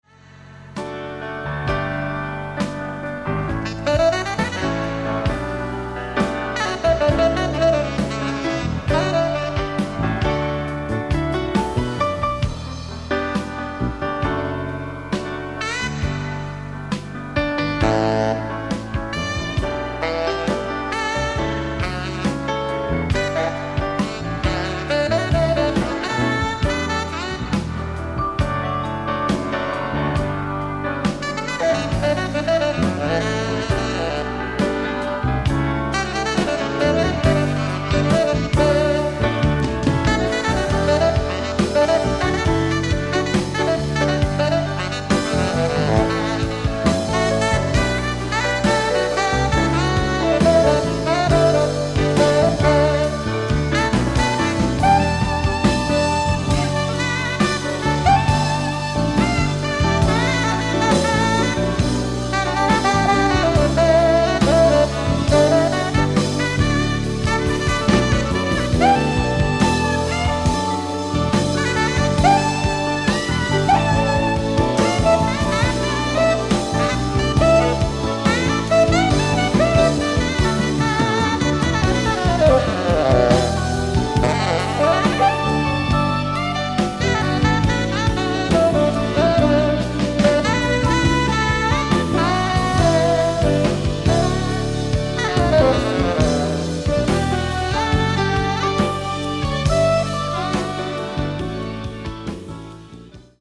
ディスク１：ライブ・アット・トゥインパビルジョーン、ハーグ、オランダ 07/14/1985
海外マニアによるピッチ補正等を施したリマスター仕様盤！！
※試聴用に実際より音質を落としています。